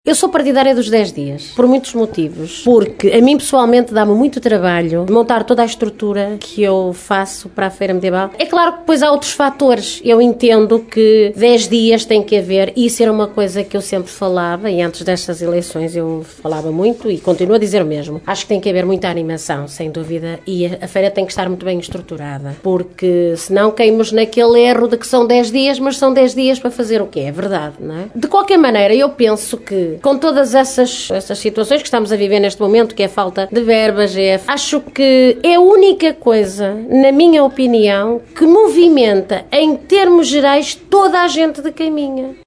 A Rádio Caminha saiu à rua para ouvir comerciantes e instituições do concelho e percebeu que as opiniões dividem-se, mas a maioria dos auscultados não concorda com a decisão do presidente da Câmara.